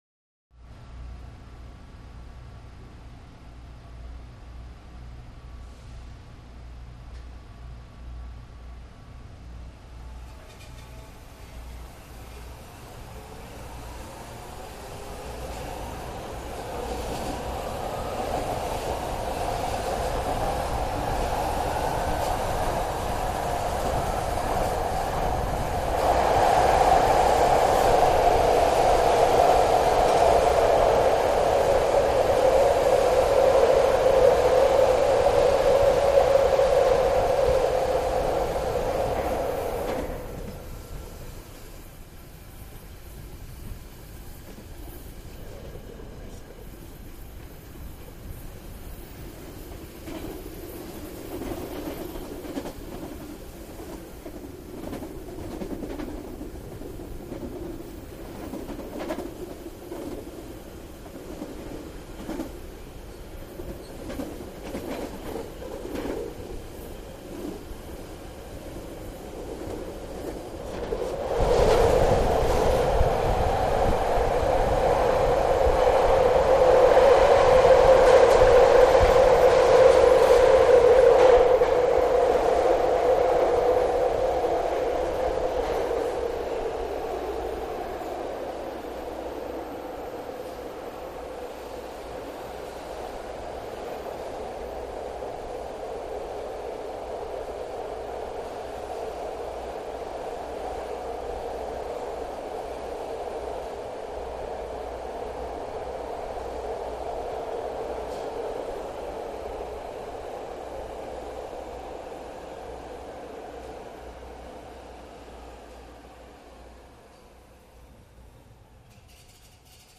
BART Subway
Start / Away ( Exterior ); Bart Train Goes Into Tunnel.